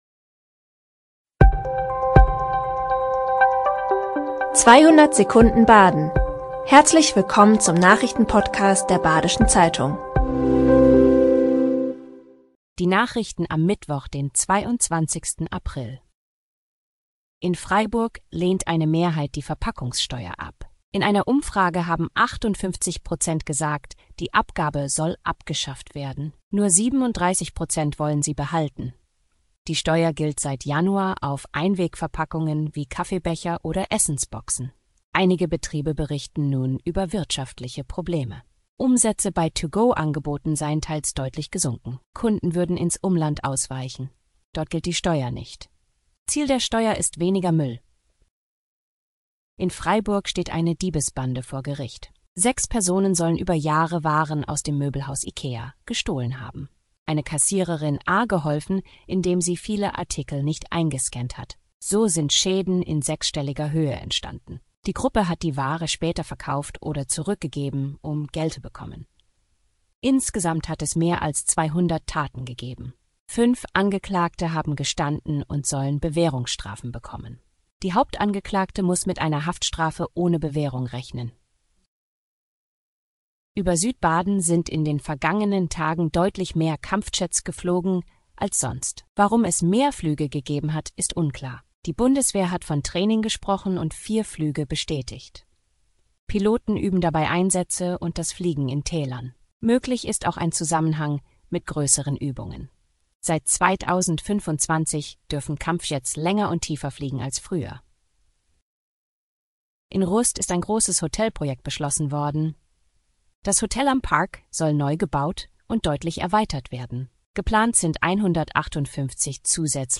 5 Nachrichten in 200 Sekunden.